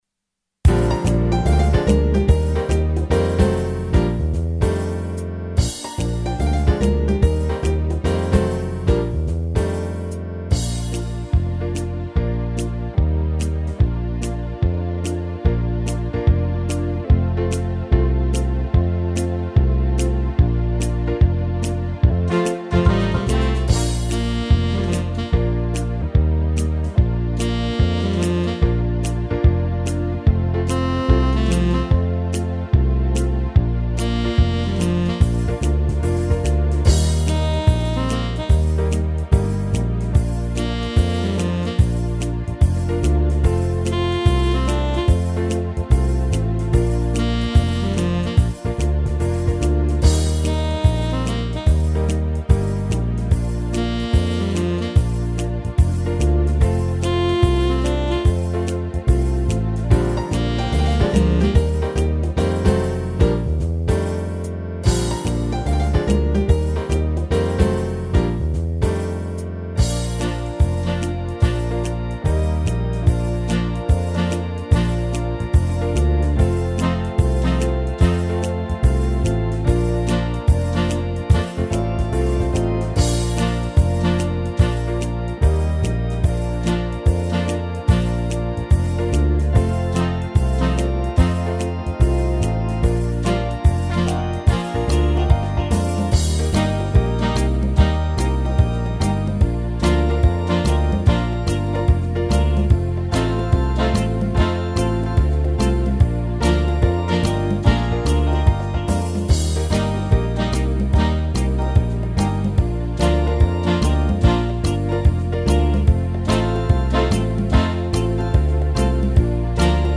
Kategorie: Playbacks-KARAOKE